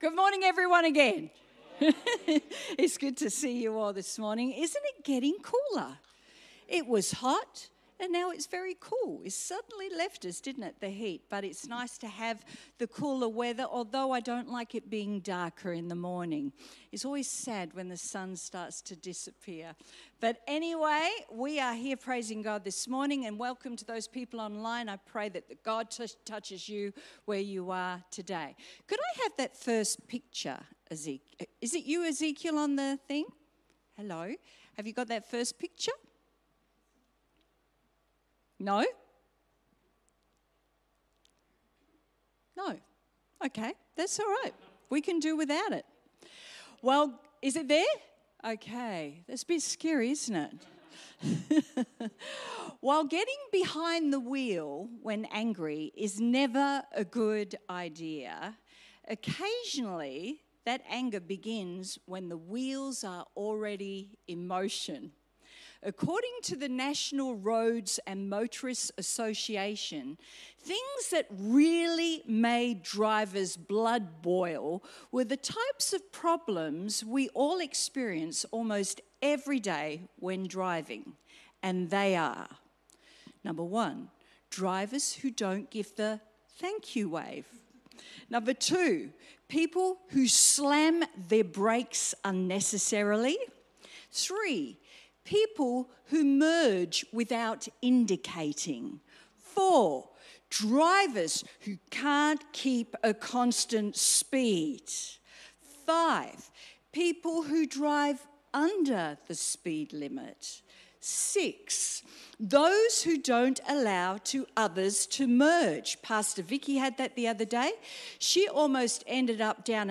How should Christians handle their anger even if it is justified? sermon transcript While getting behind the wheel when angry is never a good idea, occasionally that anger begins when the wheels are already in motion.